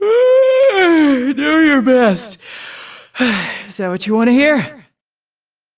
p4u-nav-yawn.opus